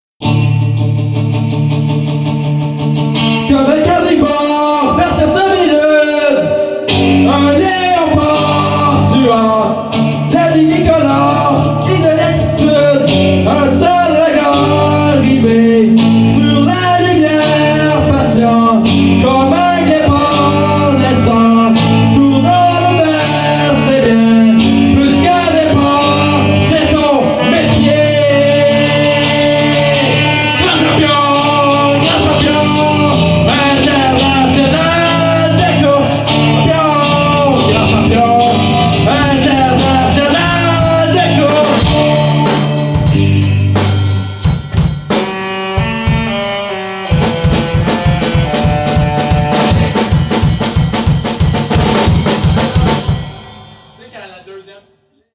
where: Chez BAM
Cover